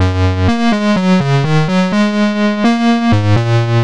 Happy Rhodes_125_Gb.wav